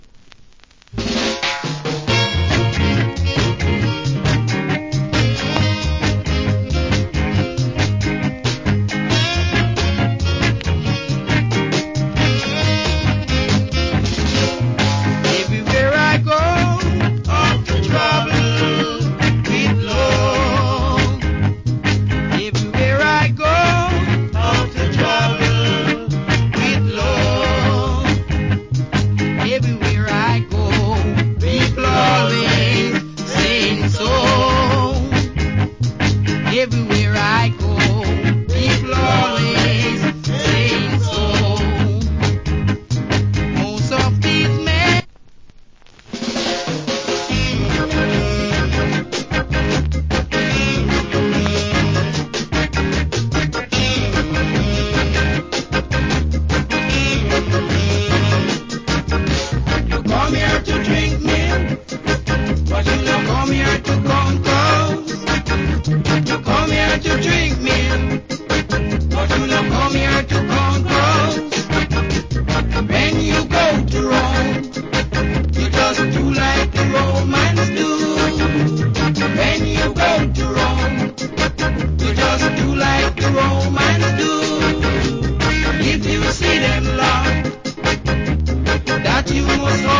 Great Early Reggae Vocal.